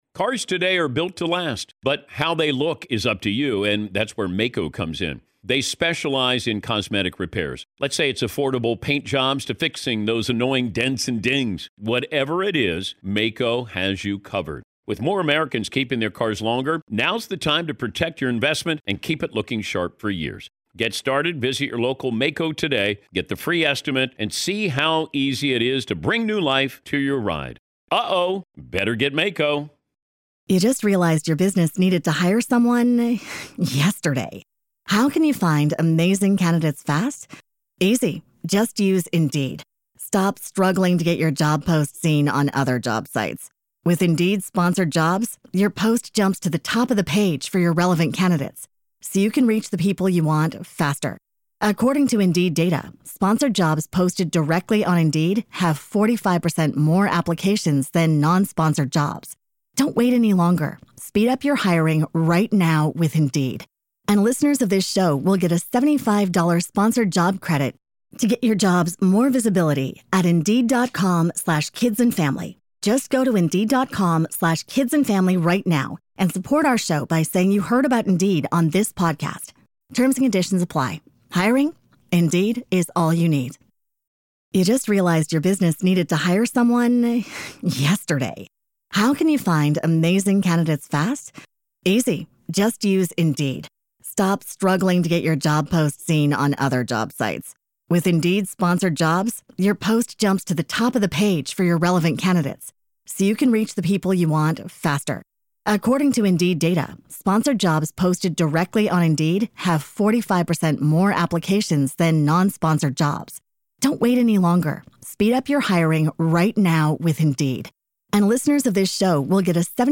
Could the Playboy Mansion be haunted? We sit down with Bridget Marquardt—Playmate, paranormal enthusiast, and one of Hugh Hefner’s former girlfriends—to uncover the ghost stories, eerie moments, and secrets that still linger in the mansion's walls.